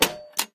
eject_scrap_button_01.ogg